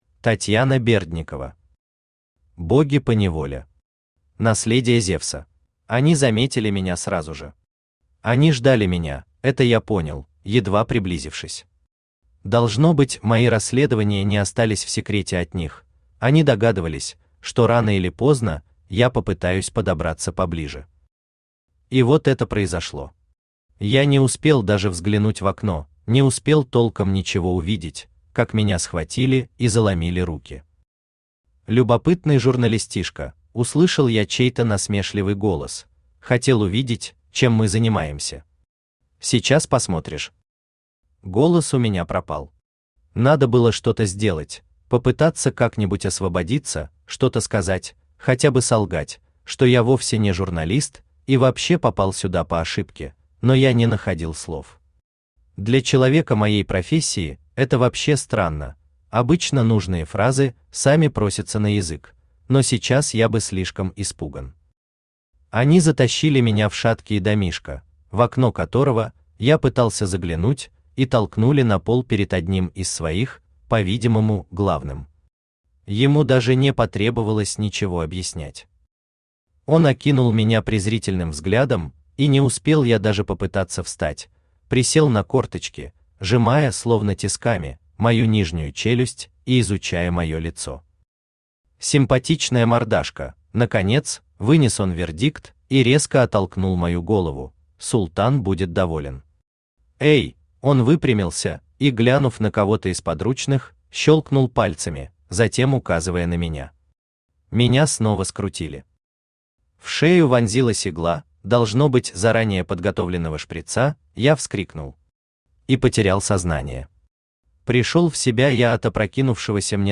Аудиокнига Боги поневоле. Наследие Зевса | Библиотека аудиокниг
Наследие Зевса Автор Татьяна Андреевна Бердникова Читает аудиокнигу Авточтец ЛитРес.